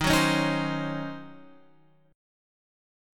D# Minor 13th